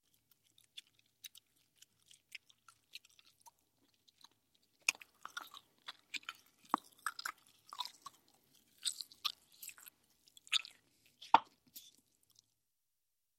Звук чавканья жвачки с открытым ртом